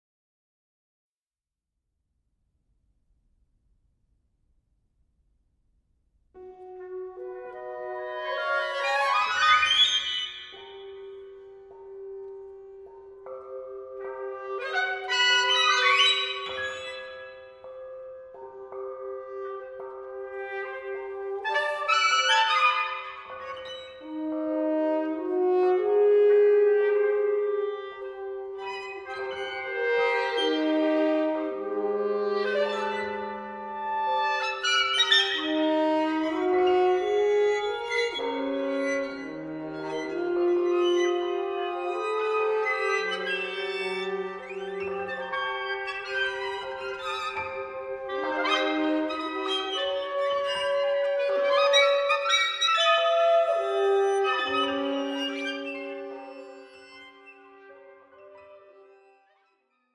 Voicing: Full Orche